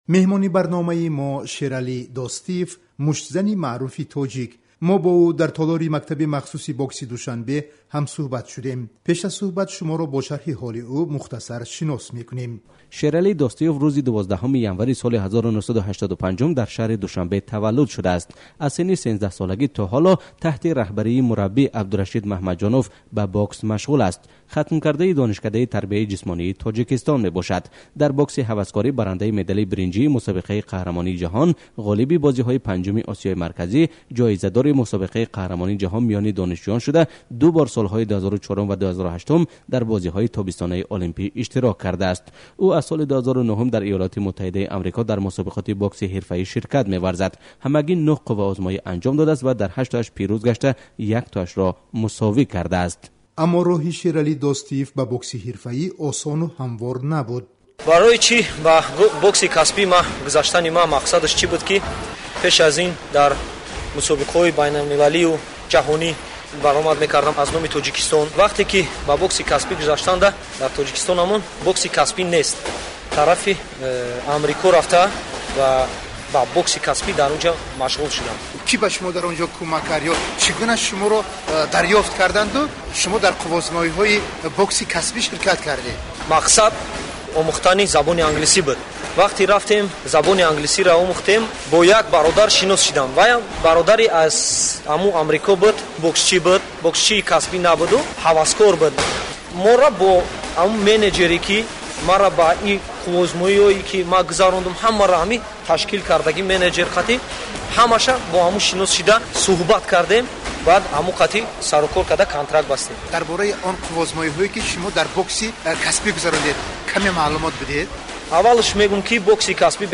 Гуфтугӯи